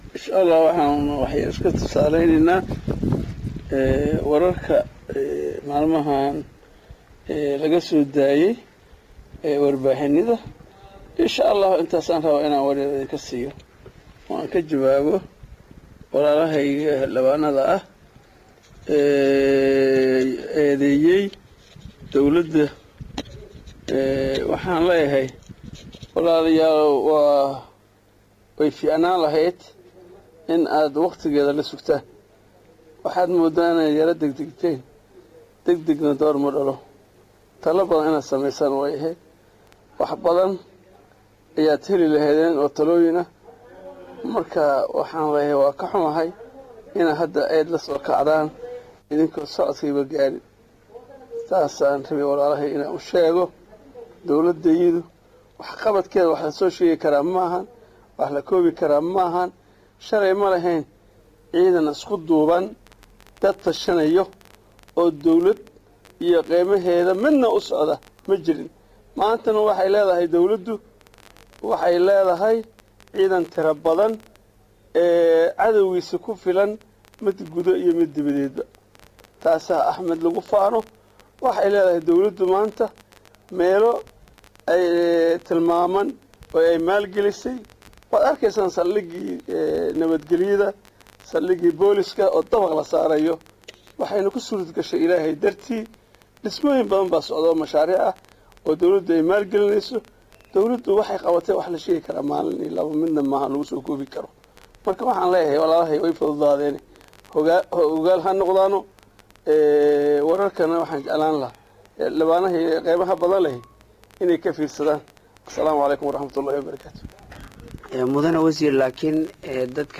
Wasiirka Wasaaradda Hidaha iyo tacliinta Sare Ee koonfur Galbeed Soomaaliya Xasan Ibrahim ayaa oo wareysi Siyey Warbaahinta Idale News Online ayaa sheegey in Dowlada KGS ay qabatey waxyaabo badan  isla markanaa uu xusey in aysan Habooneynin in Lagu deg dego dhaliilo iyo eedeymo loo jeediyo Dowlada.